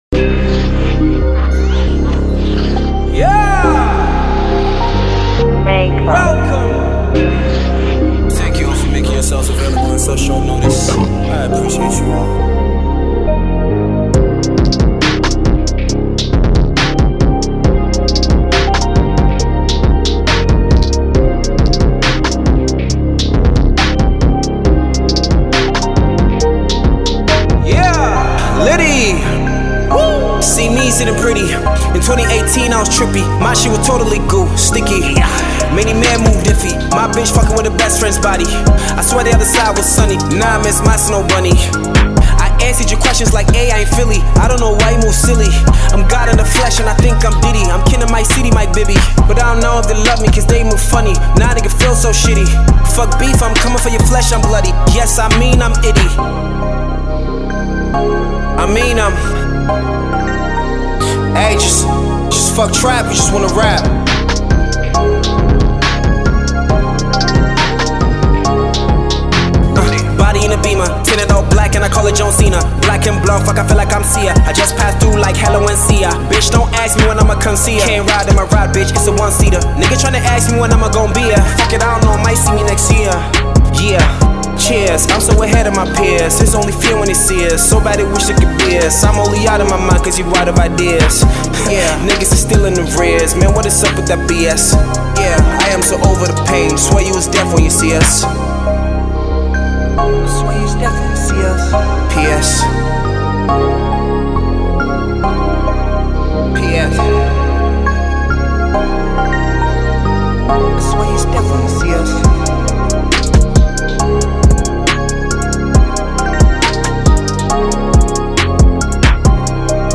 hip hop tune